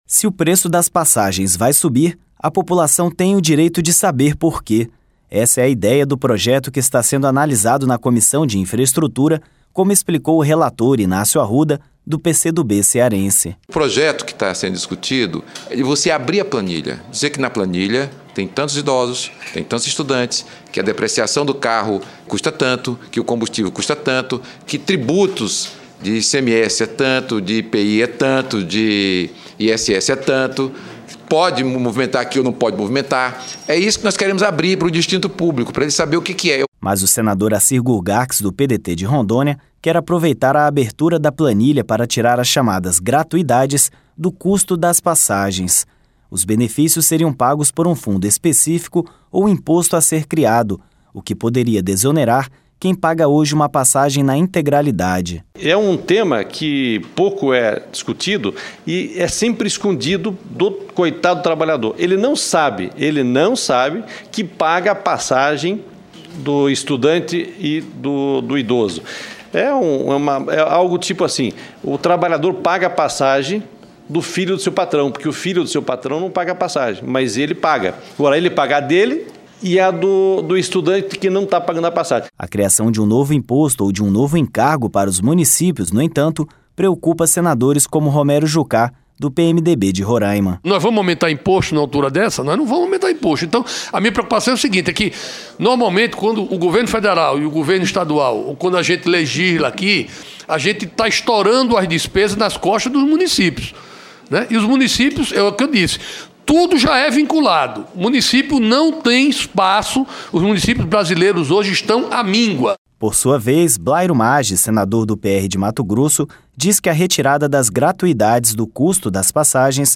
Rádio Senado